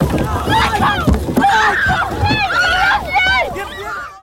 Crowd Panic Yelling Fade Out